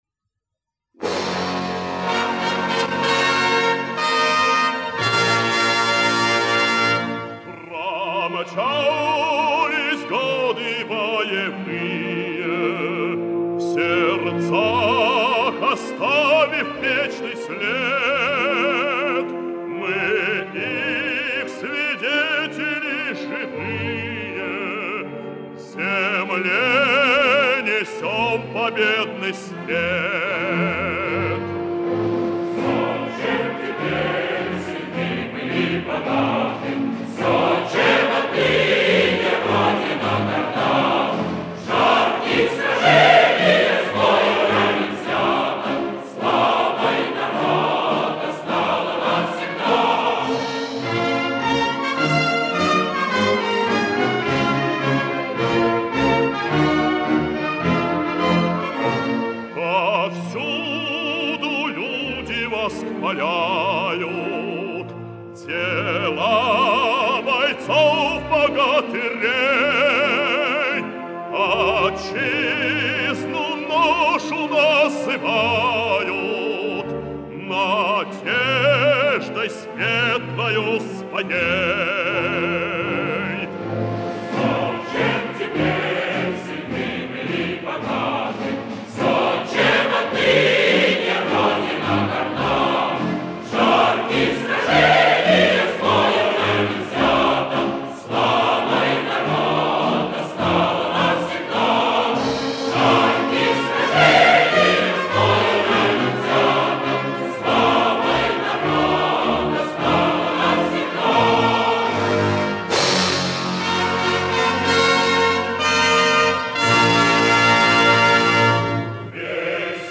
хор